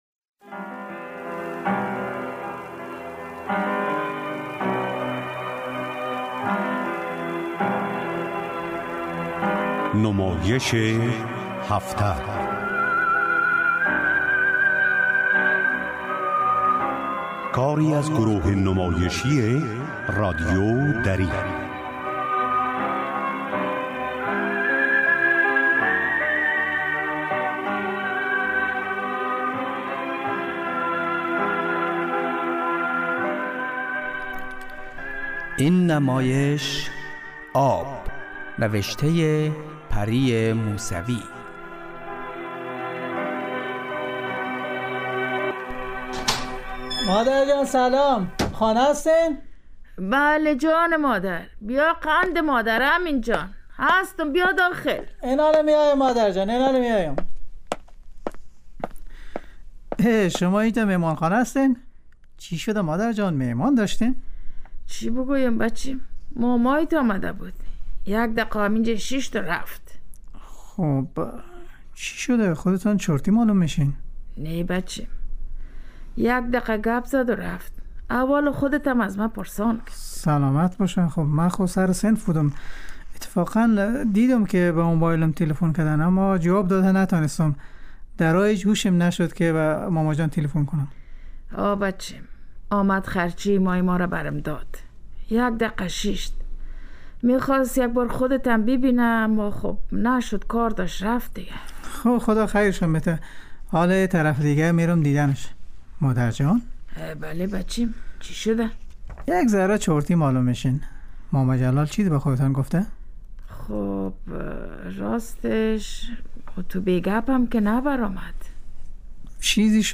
نمايش هفته